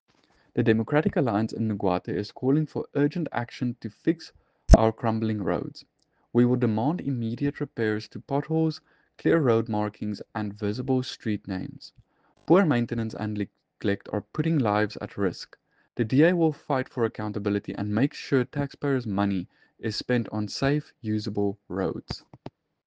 Afrikaans soundbites by Cllr JP de Villiers and